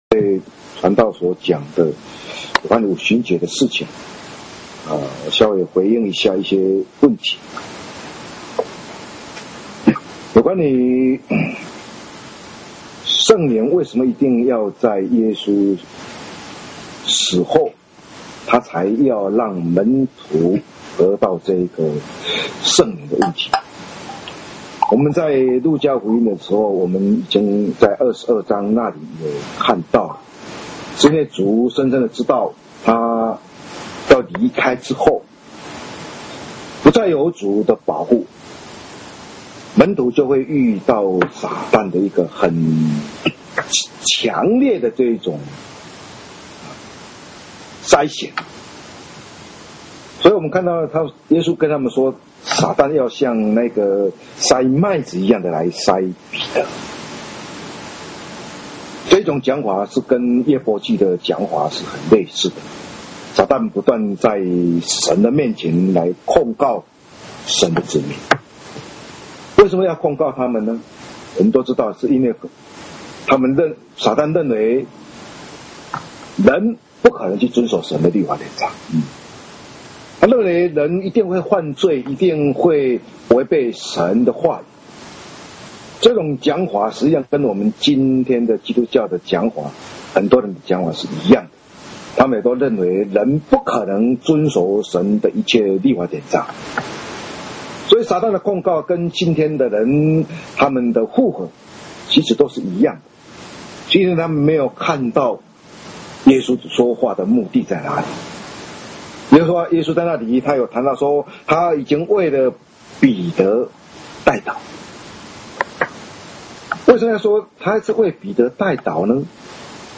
2013年五旬節聖會-禱告